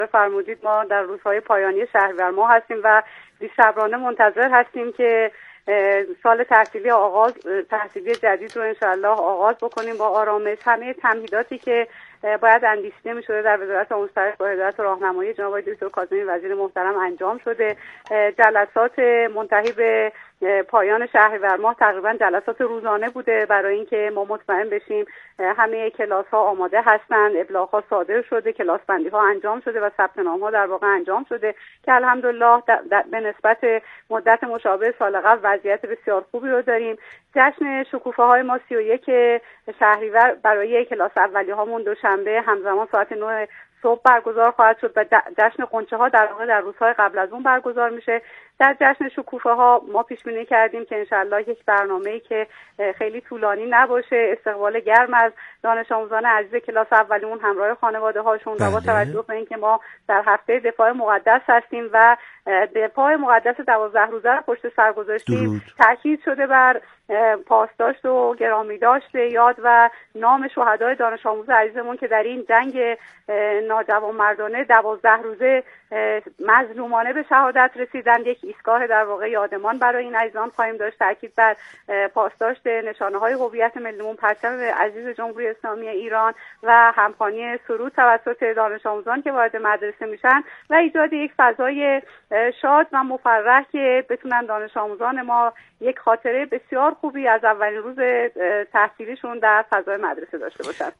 ایکنا به پاس همین اهمیت والا و با درک ژرف از این مسئولیت خطیر، به گفت‌وگو با رضوان حکیم‌زاده، معاون آموزش ابتدایی وزارت آموزش و پرورش نشسته است تا از روایت‌های او در زمینه تحول آموزشی، عدالت محوری و همگامی با نظام آموزش و پروش با فناوری‌های روز در مسیر برداشتن گامی هر چند کوچک در راه اعتلای آموزش و پرورش میهن عزیزمان بگوید و بشنود.